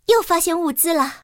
野蜂获得资源语音.OGG